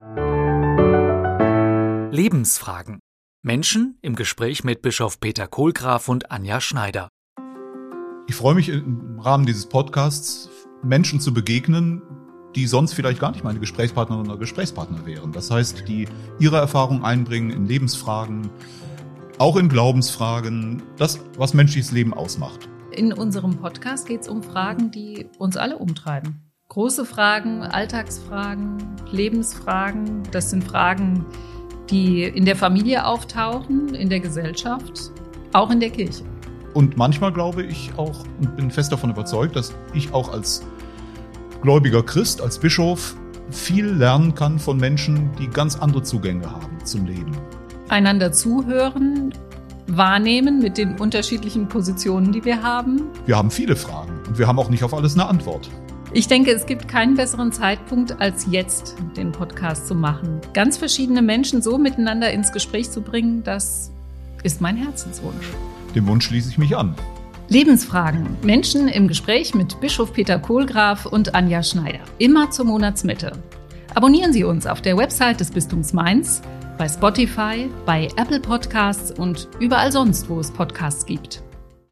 Menschen im Gespräch mit Bischof Peter Kohlgraf
Im Podcast „Lebensfragen“ sprechen ein katholischer Bischof und